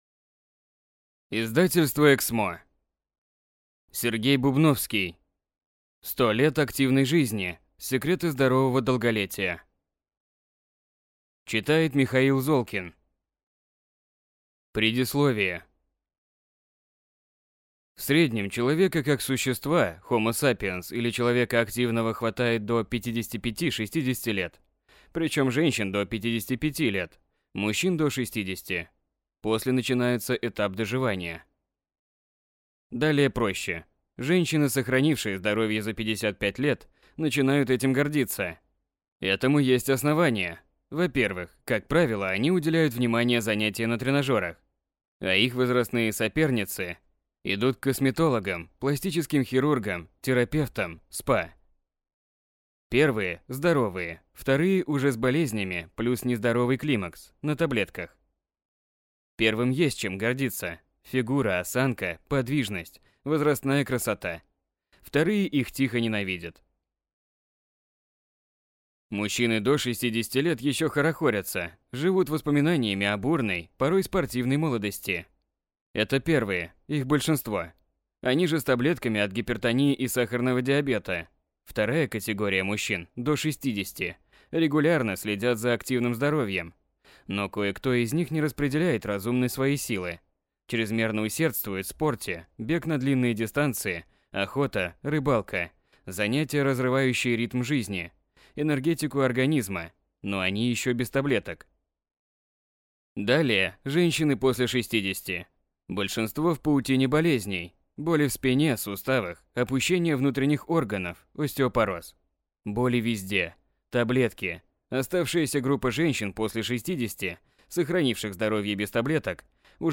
Аудиокнига 100 лет активной жизни, или Секреты здорового долголетия. 1000 ответов на вопросы, как вернуть здоровье | Библиотека аудиокниг